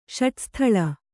♪ ṣaṭsthaḷa